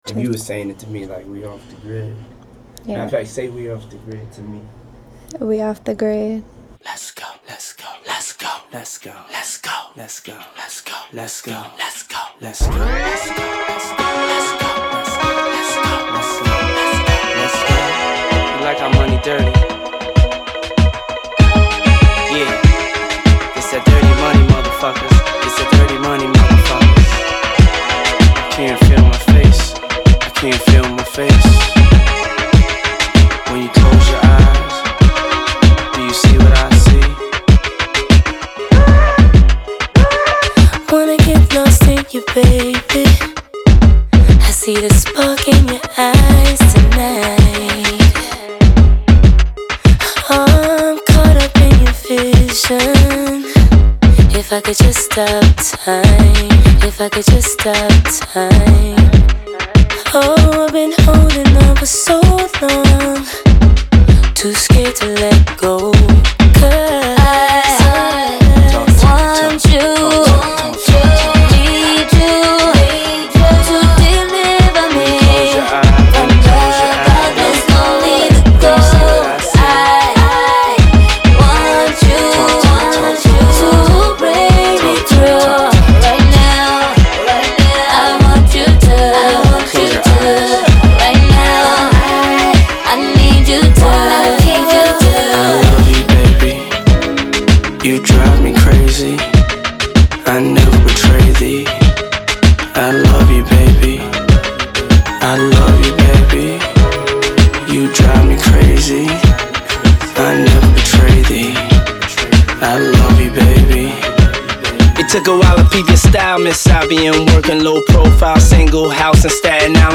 Genre : Soul, Funk, R&B